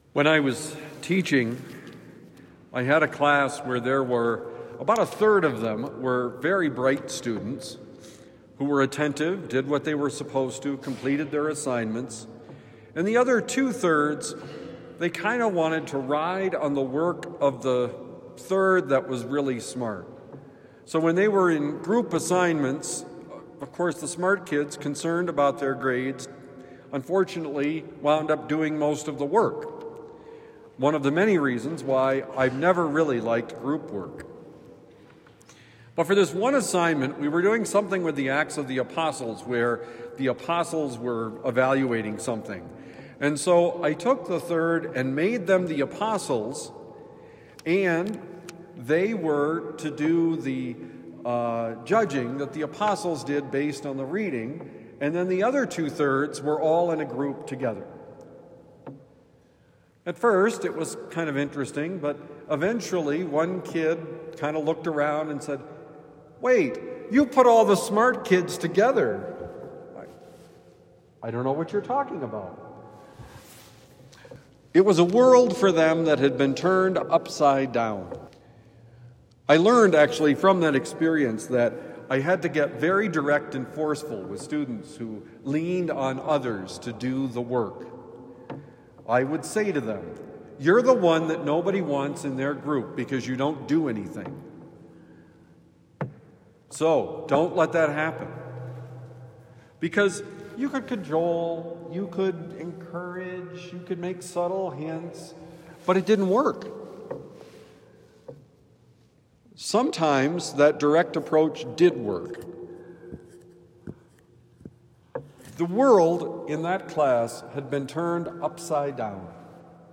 Turned Upside Down: Homily for Thursday, December 4, 2025